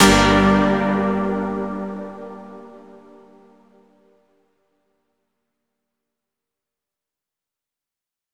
Chords_G_03.wav